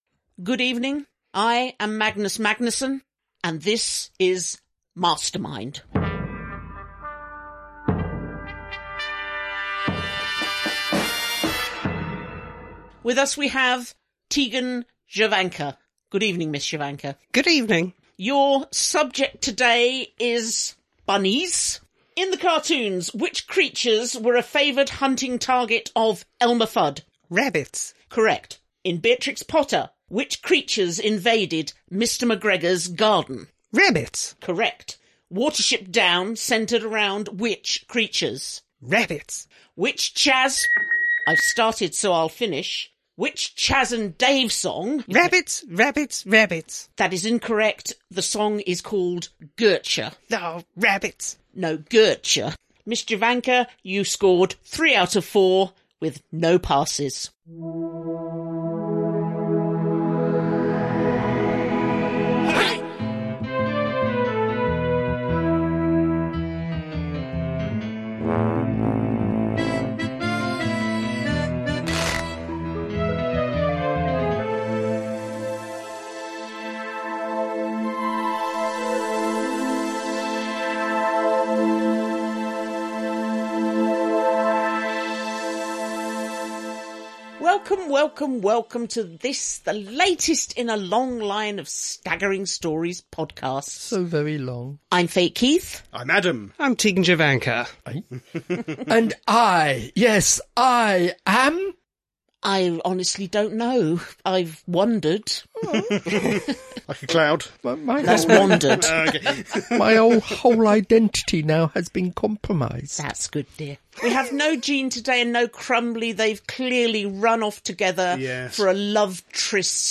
00:00 – Intro and theme tune.
50:56 — End theme, disclaimer, copyright, etc.